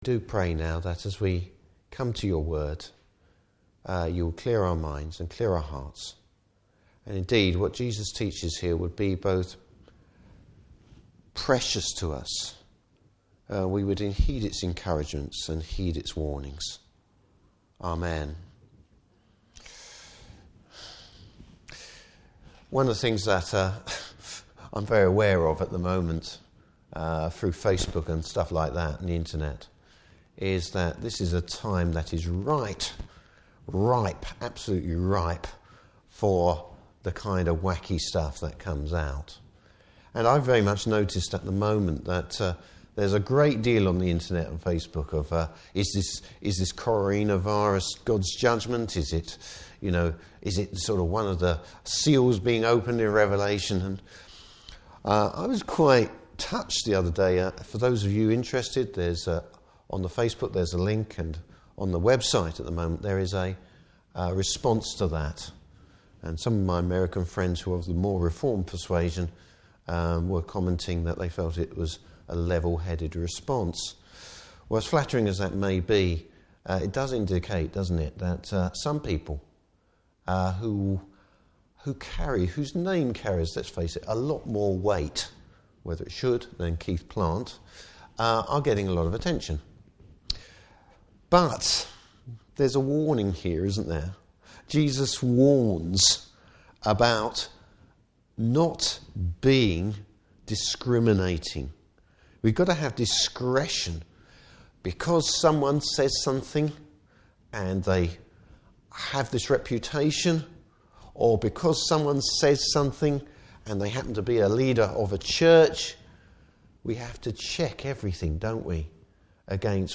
Service Type: Morning Service Bible Text: Luke 12:1-12.